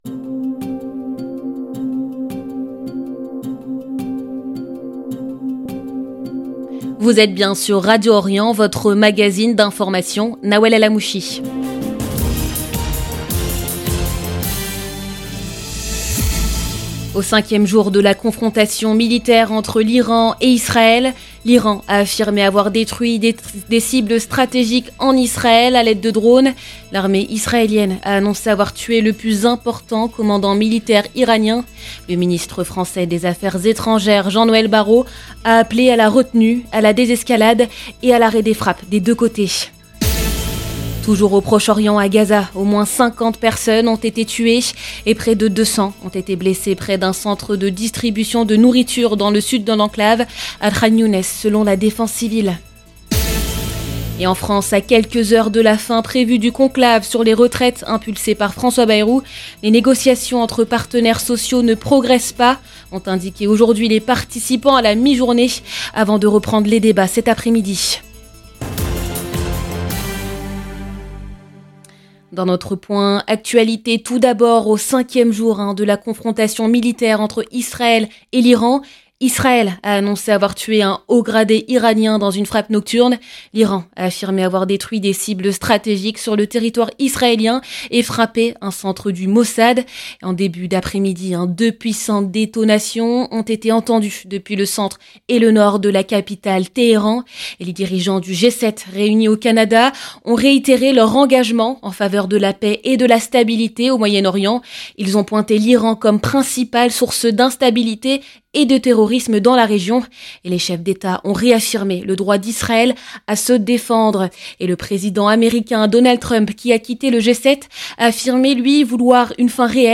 Magazine de l'information de 17H 00 du mardi 17 juin 2025